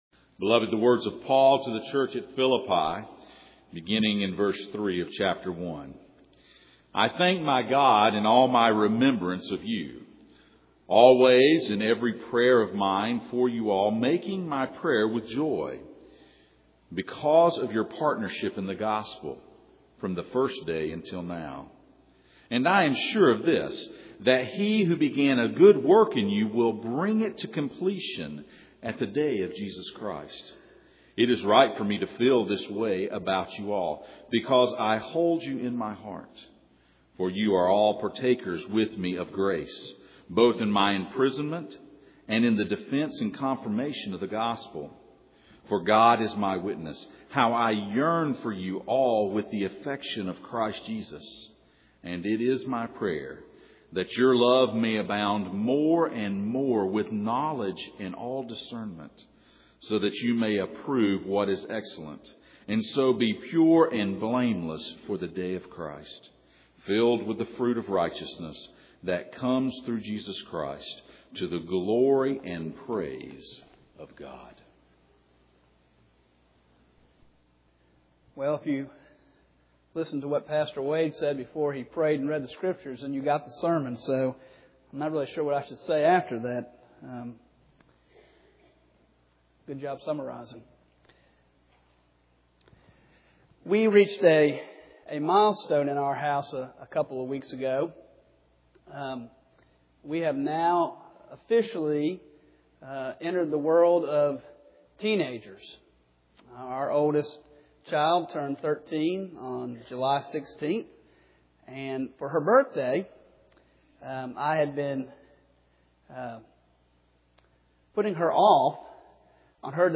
The Golden Chain Passage: Philippians 1:3-11 Service Type: Sunday Morning « The Golden Chain